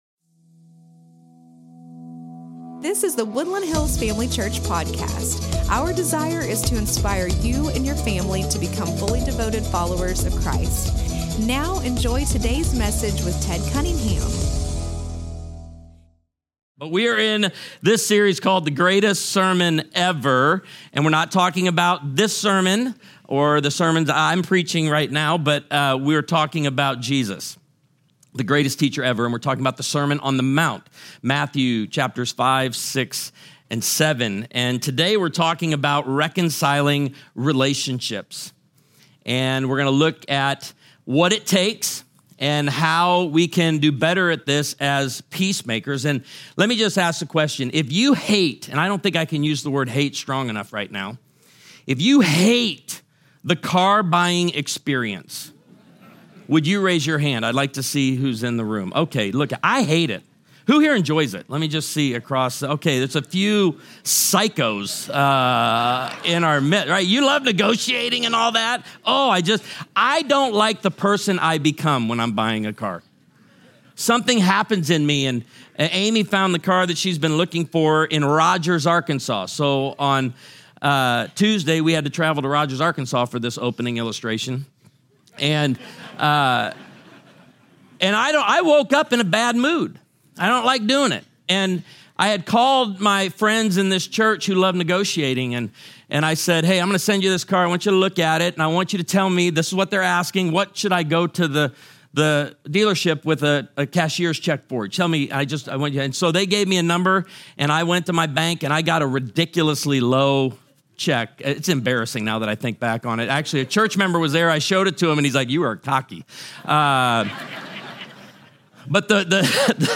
The Greatest Sermon Ever (Part 4)